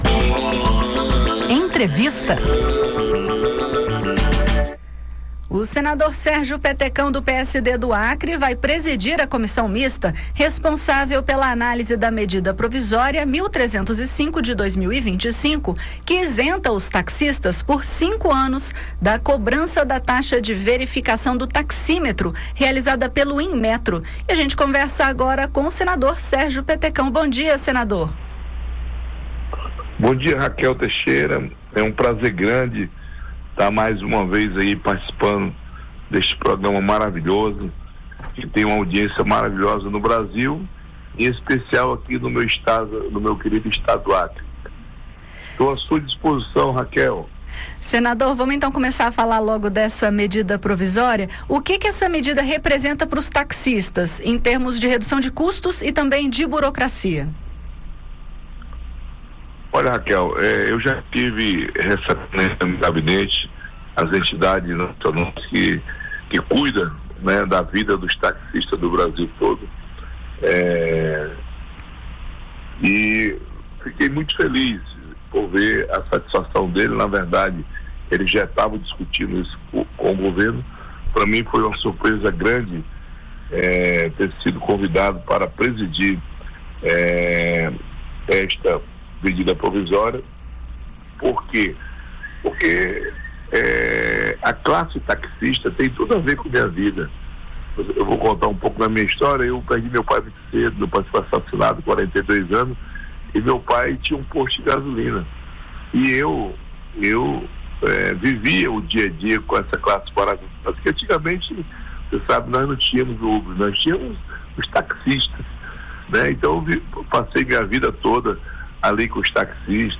Essa medida isentou os taxistas, por cinco anos, da cobrança da taxa de verificação do taxímetro realizada pelo Inmetro. Ouça a entrevista com o senador, que defende a isenção.